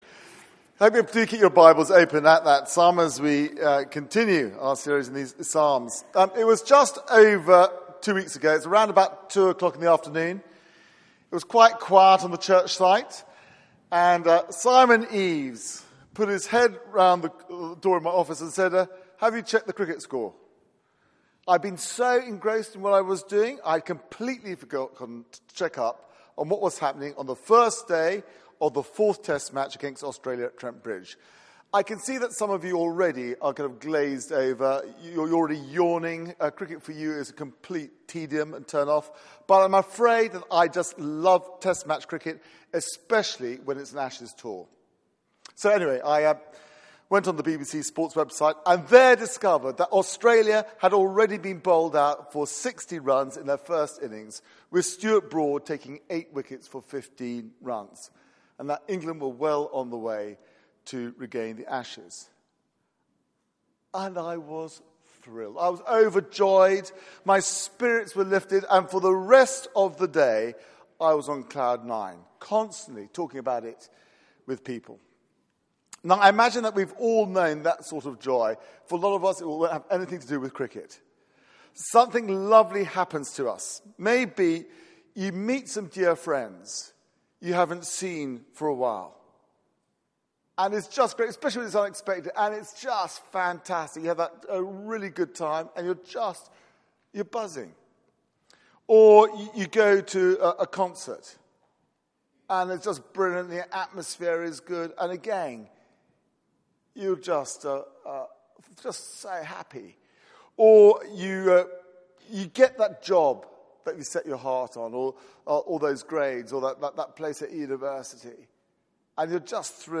Passage: Psalm 33 Service Type: Weekly Service at 4pm